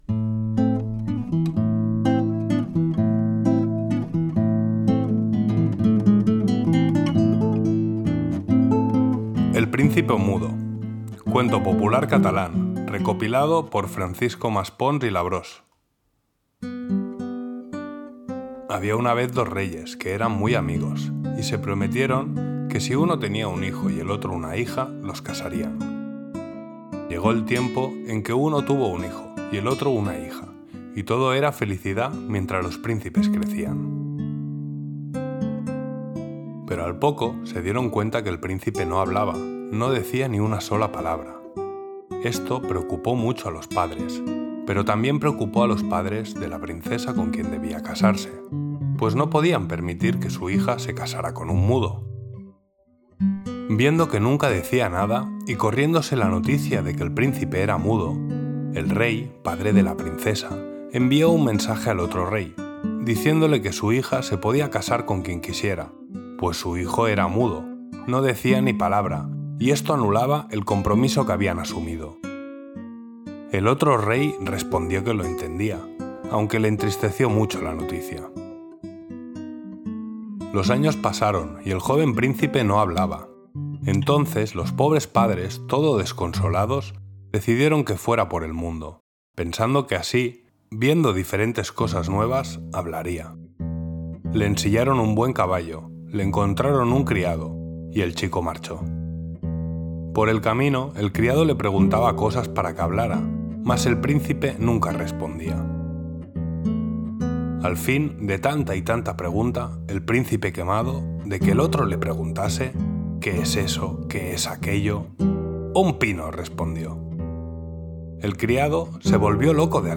Música renacentista para laúd.
Música de guitarra